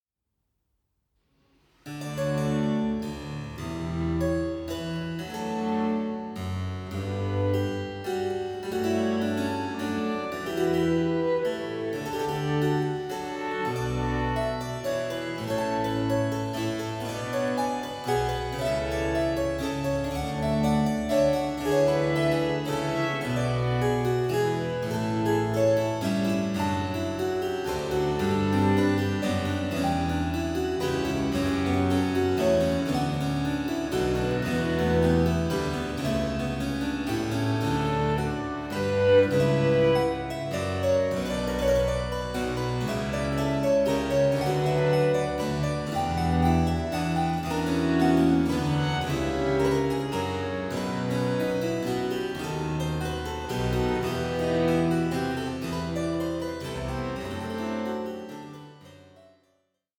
Concerto for 2 Harpsichords in C Minor
Andante 05:38